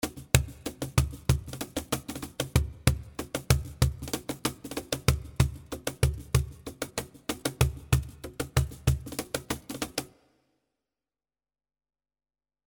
95 BPM cajon (13 variations)
The cajon loops are in 95 bpm playing baladi style.
The free download loops are with reverb 2 seconds long, but by buying this product